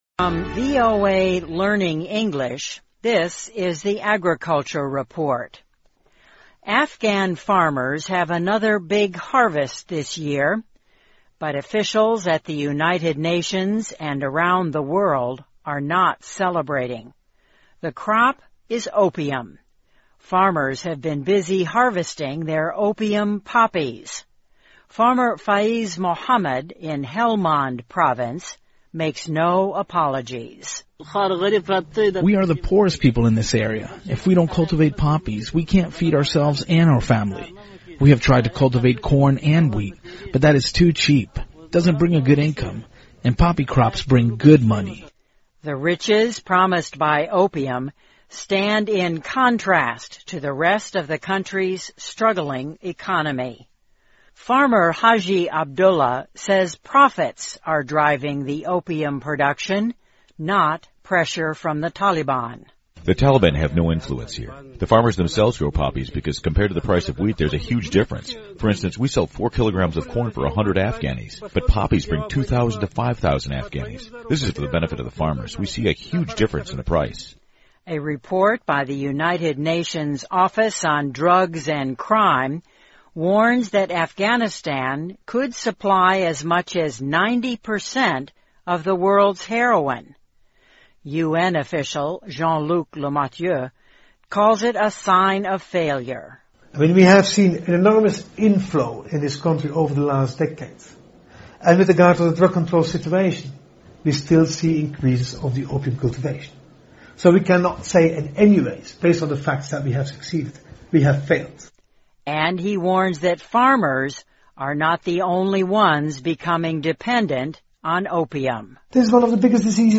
VOA慢速英语2013 农业报道 - 阿富汗鸦片种植连年增加 听力文件下载—在线英语听力室